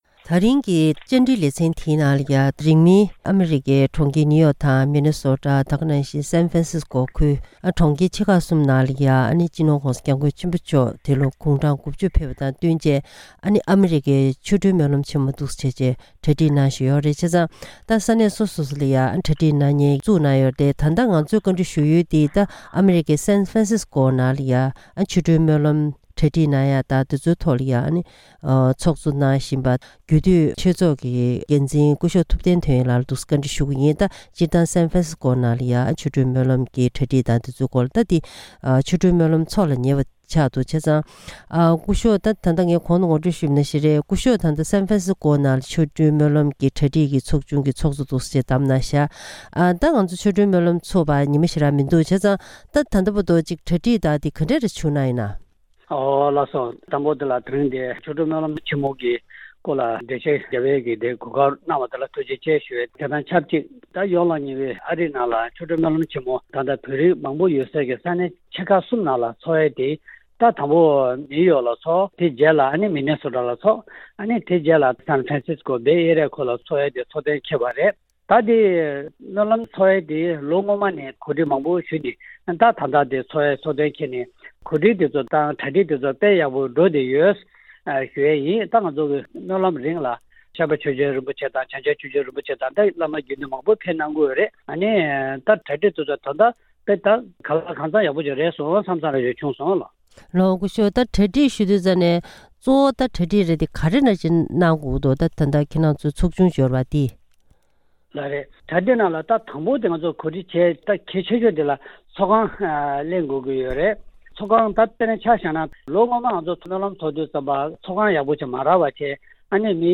ཐེངས་འདིའི་བཅར་འདྲིའི་ལེ་ཚན་ནང་། ༧སྤྱི་ནོར་༧གོང་ས་སྐྱབས་མགོན་ཆེན་པོ་མཆོག་འདི་ལོ་གུང་གྲངས་༩༠ ཕེབས་པ་དང་བསྟུན་ཨ་རིའི་ས་གནས་ཆེ་ཁག་གསུམ་ནང་ཨ་རིའི་ཆོ་འཕྲུལ་སྨོན་ལམ་དང་གོ་སྟོན་སྲུང་བརྩི་རྒྱུ་ཡིན་པ་ལྟར་། ཨ་རིའི་California ས་གནས་ནང་ཆོ་འཕྲུལ་སྨོན་ལམ་དང་གོ་དོན་གྱི་མཛད་སྒོ་གྲ་སྒྲིག་གནང་སྟངས་སོགས་ཀྱི་སྐོར་བཀའ་འདྲི་ཞུས་པ་ཞིག་གསན་རོགས་གནང་།